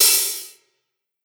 Dub Hat.wav